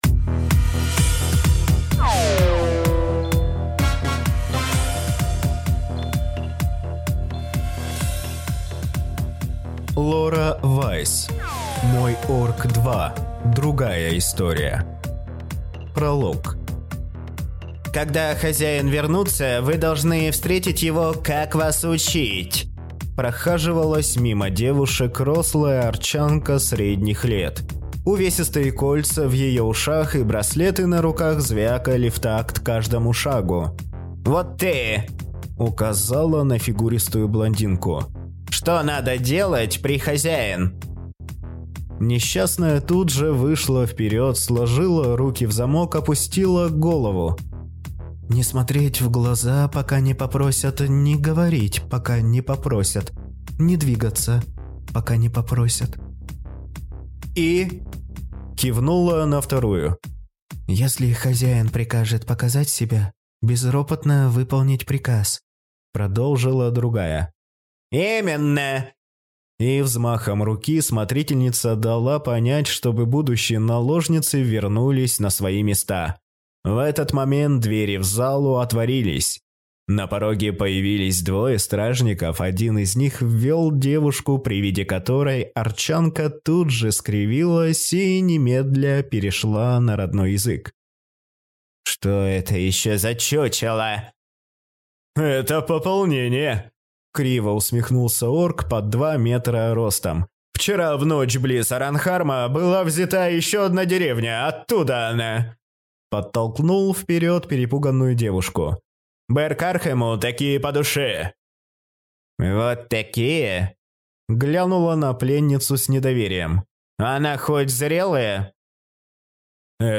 Аудиокнига Мой орк 2. Другая история | Библиотека аудиокниг